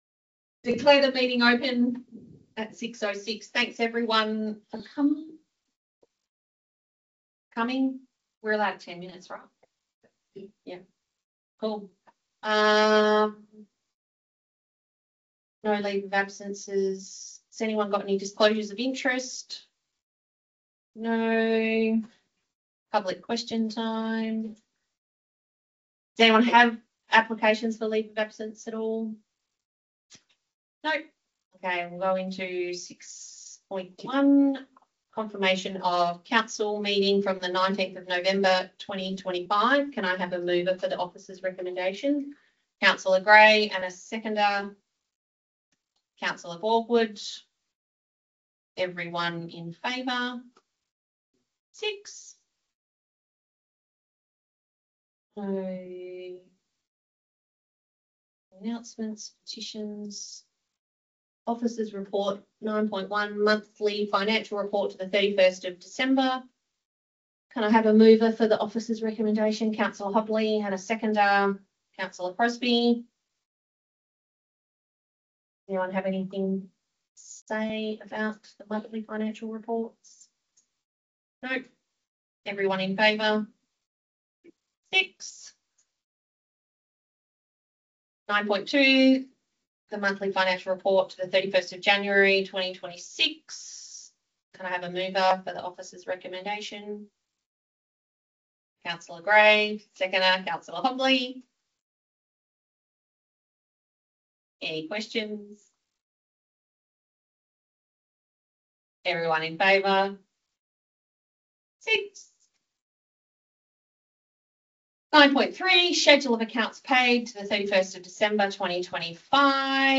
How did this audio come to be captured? Audio Recording – Ordinary Council Meeting 11 February 2026